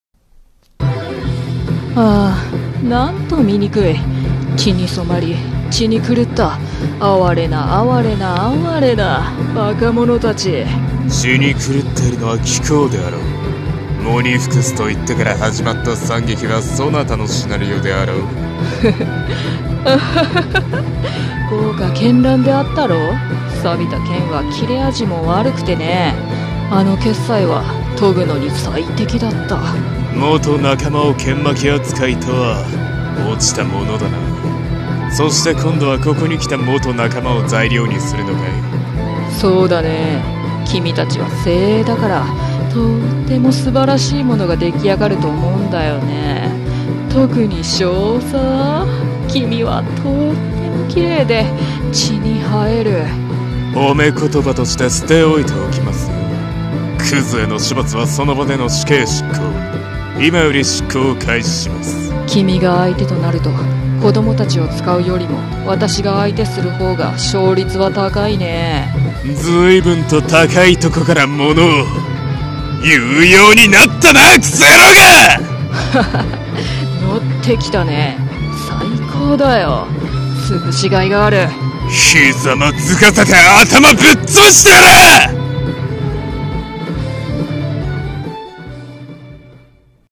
【声劇】狂気血演〜祭〜